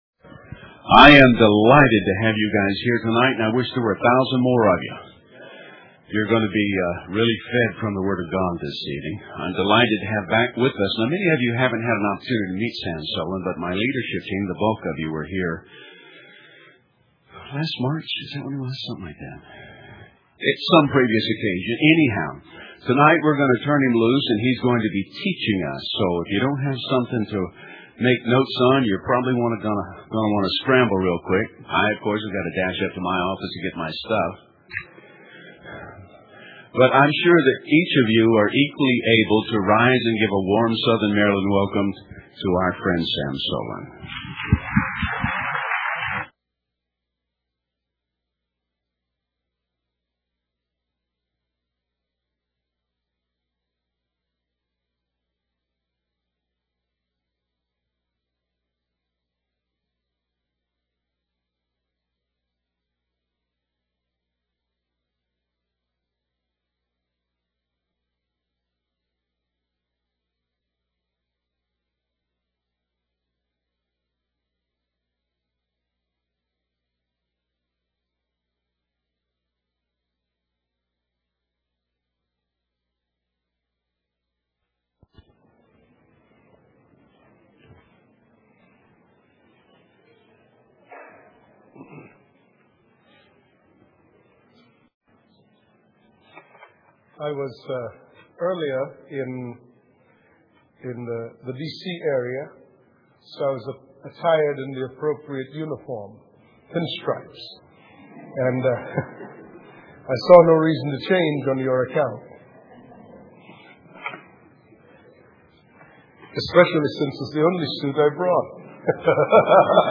Evening meeting